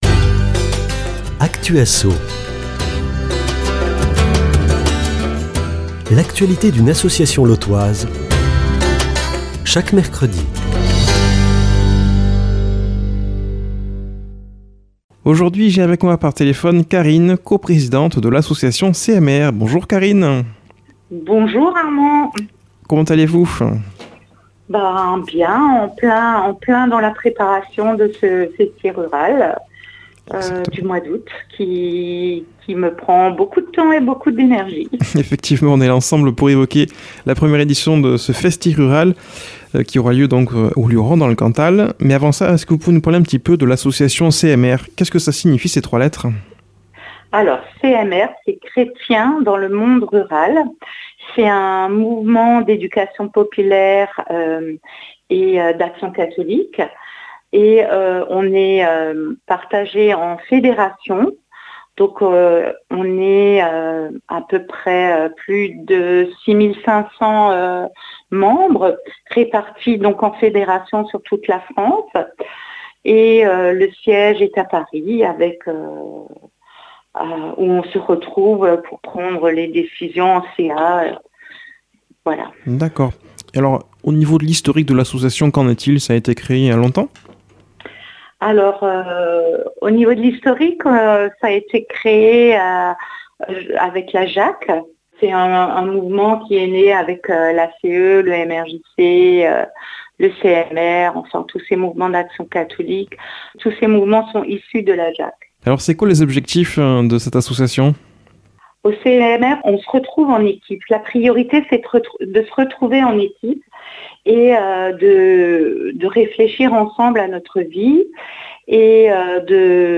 a comme invitée par téléphone